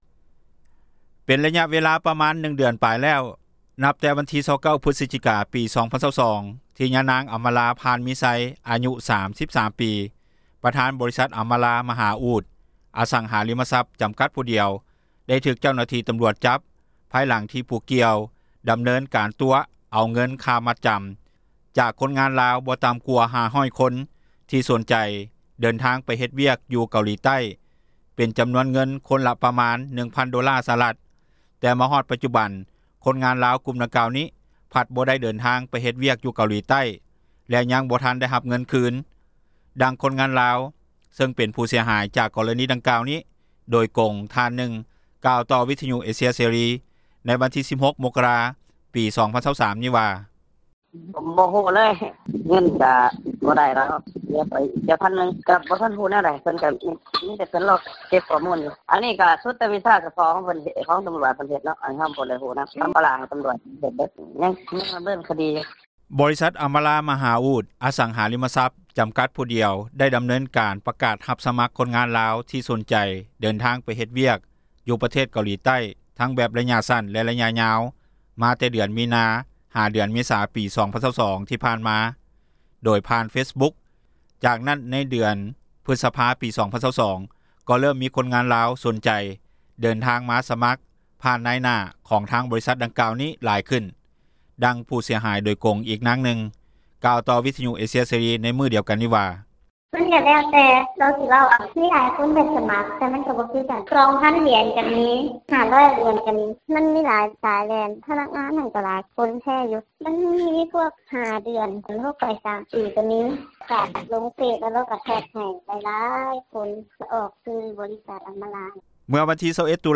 ດັ່ງຜູ້ເສັຽຫາຍ ໂດຍຕຣົງອີກນາງນຶ່ງ ກ່າວຕໍ່ວິທຍຸ ເອເຊັຽ ເສຣີ ໃນມື້ດຽວກັນນີ້ວ່າ:
ດັ່ງເຈົ້າໜ້າທີ່ ທີ່ຮູ້ເຫດການດັ່ງກ່າວນີ້ ຜູ້ບໍ່ປະສົງອອກຊື່ ແລະ ຕຳແໜ່ງທ່ານນຶ່ງ ກ່າວວ່າ: